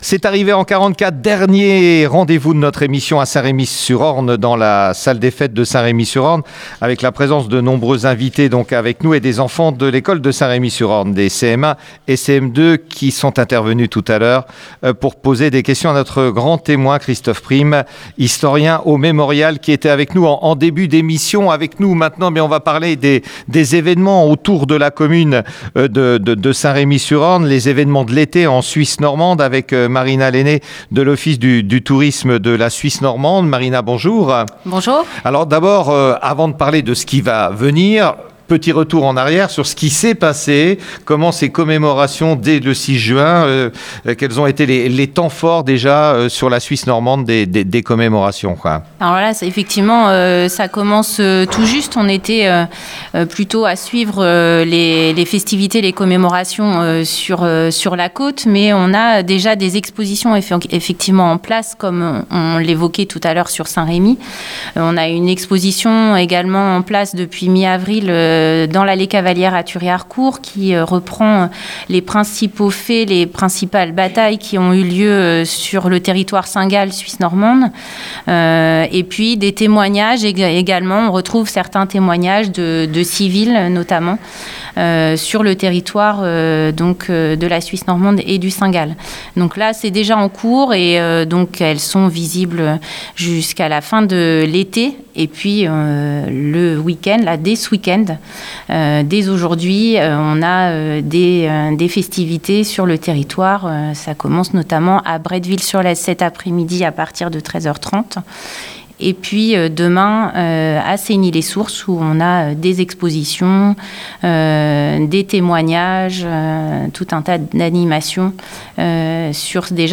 Émission exceptionnelle diffusée Dimanche 16 Juin à Saint-Remy sur Orne à 10 h 15. Témoignages, récits de la libération du Bocage.